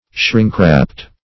shrinkwrapped.mp3